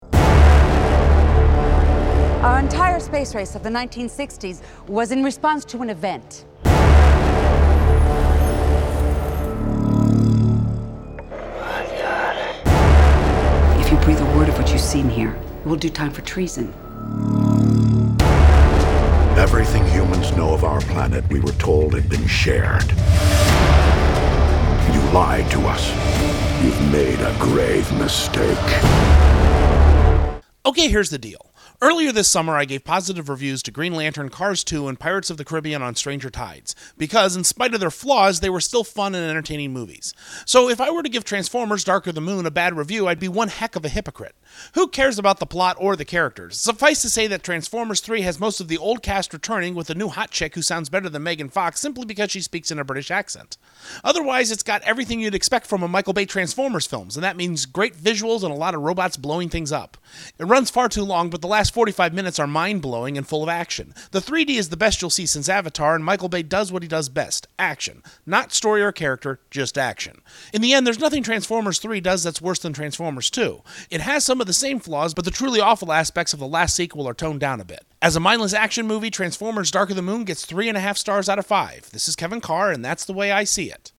Movie Review: ‘Transformers: Dark of the Moon’